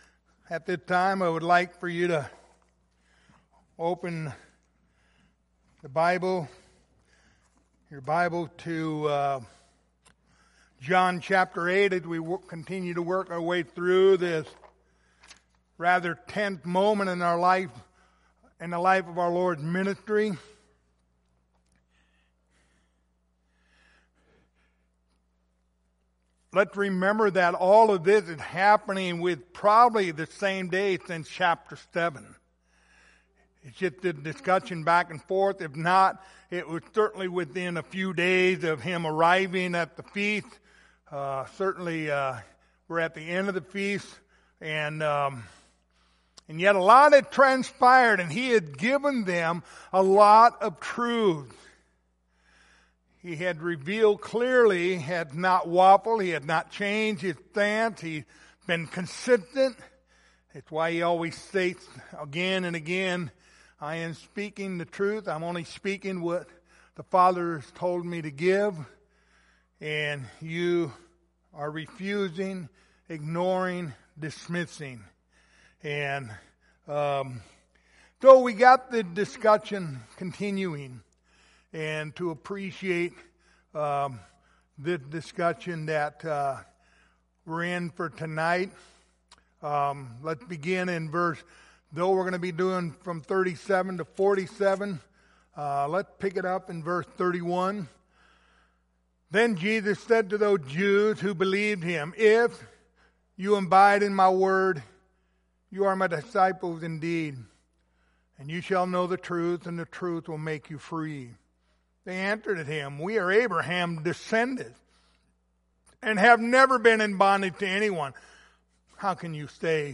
Passage: John 8:37-47 Service Type: Wednesday Evening